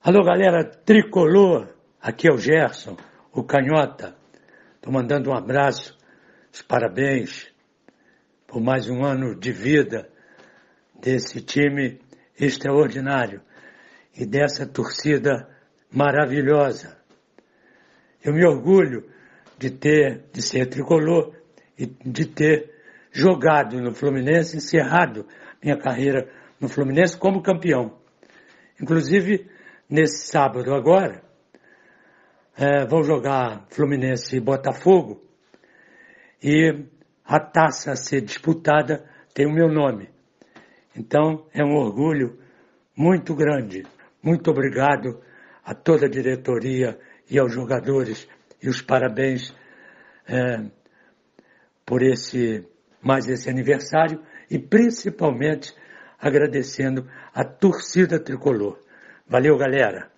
O canhotinha de ouro, Gerson, fala do carinho pelo clube e da homenagem que irá receber do time do coração.